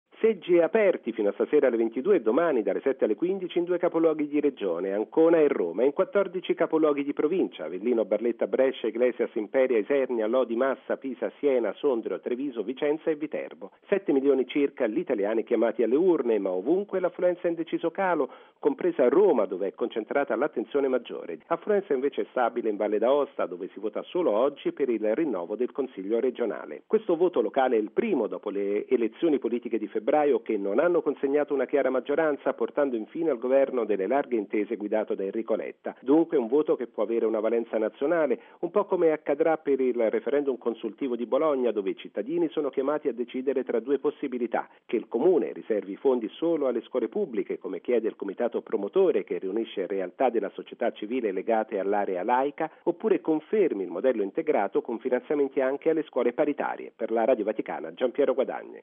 Servizio